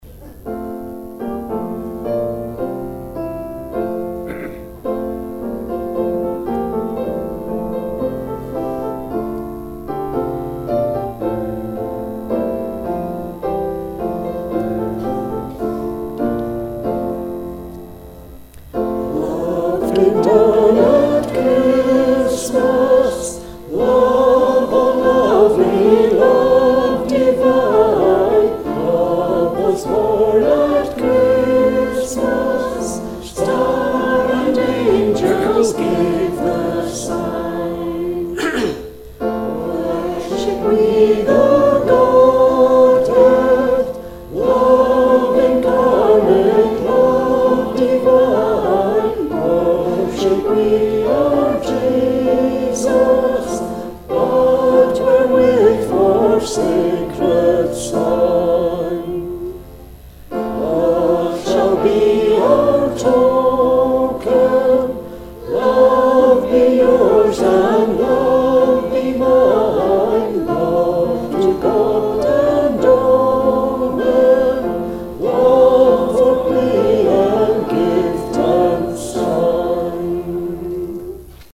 Hymn 316 'Love comes down at Christmas', before following the story.